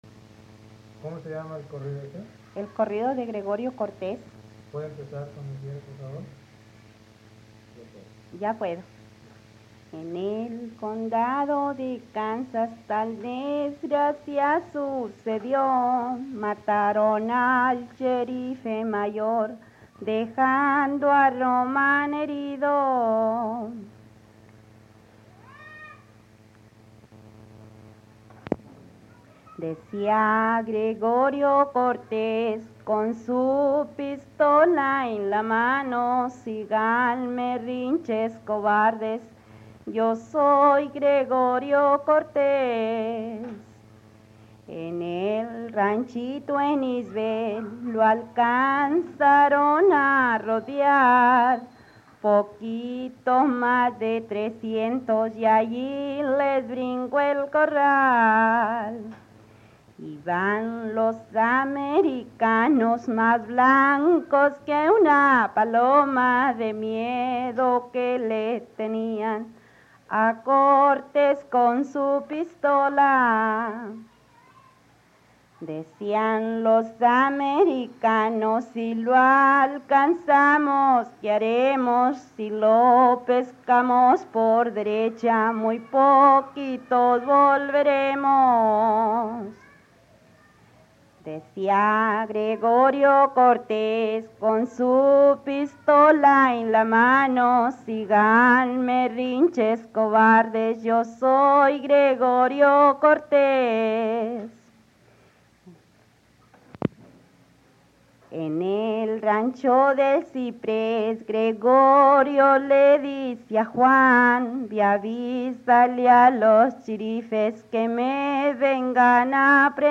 In this post, I dive deeper into the genre of corridos (narrative ballads) as they are represented in Parédes’s collection.
The practice of singing historical corridors appears to be alive and well in 1950s south Texas, which is when and where Paredes conducted the bulk of his field recordings.
Listening to a woman’s voice was refreshing, and she sings quite beautifully, without any instrumental accompaniment, in a rather pure and simple form of storytelling.